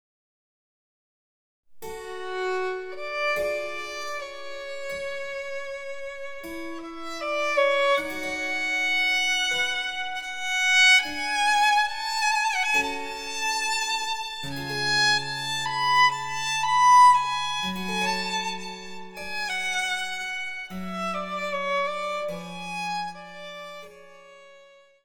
嬰ヘ短調で、簡素な旋律線ですが、痛切さに胸を打たれます。
■ヴァイオリンによる演奏（イ長調）
電子チェンバロ